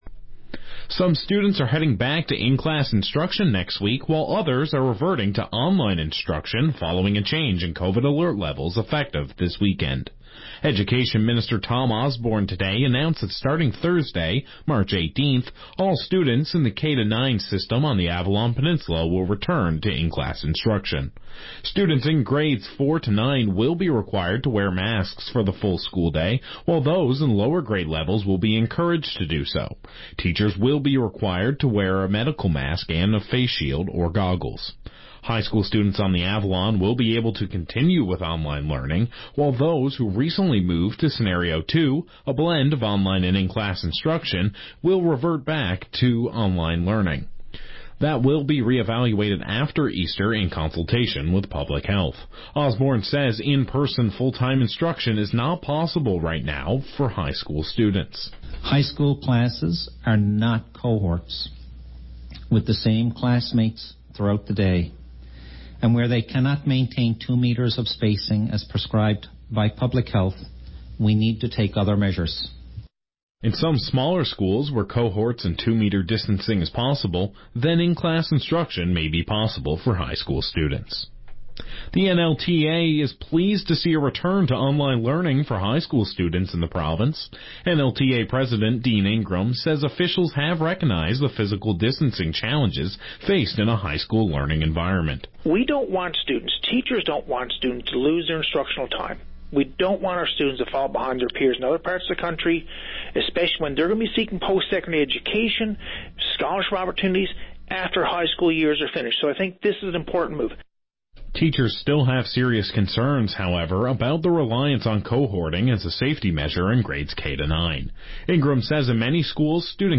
Media Interview - VOCM Evening News Mar 11, 2021